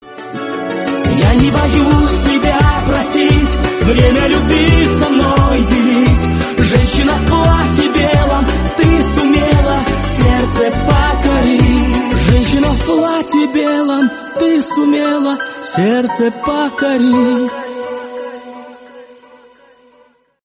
поп
гитара
мужской голос
спокойные
русская эстрада